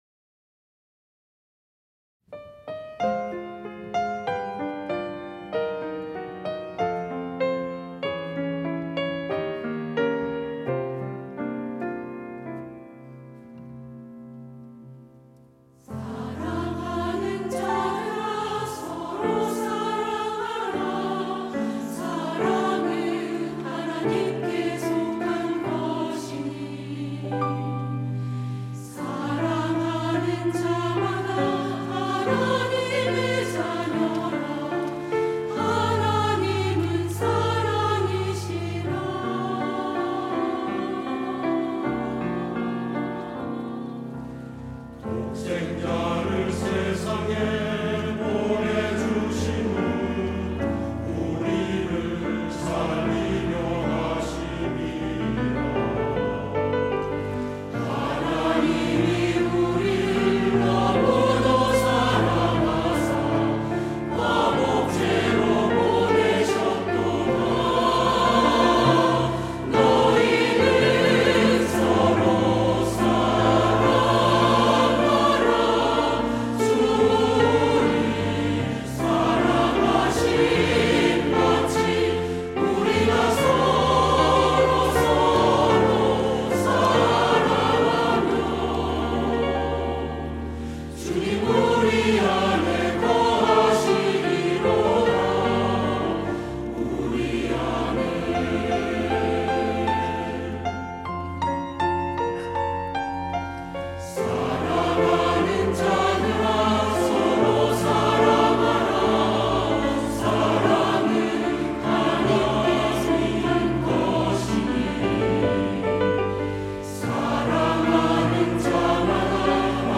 시온(주일1부) - 너희는 서로 사랑하라
찬양대